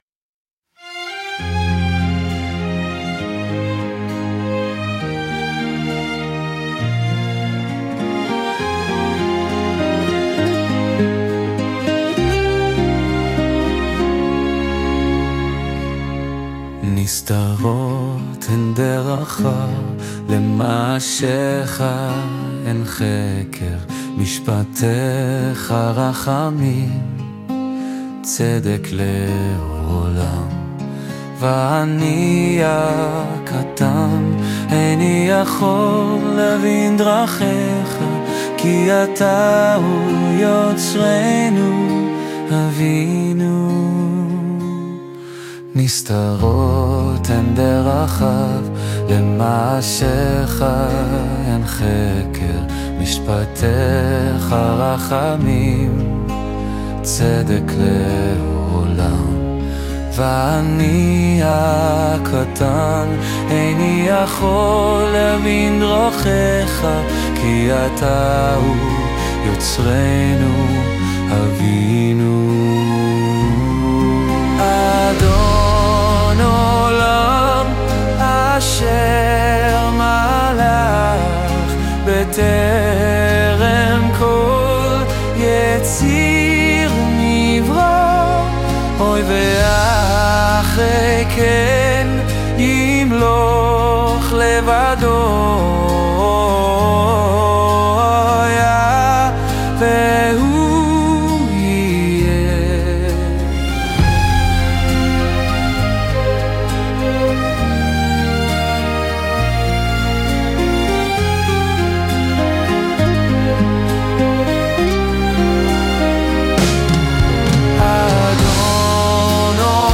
ניסיתי לעשות את זה בסגנון אופראי